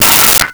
Metal Lid 01
Metal Lid 01.wav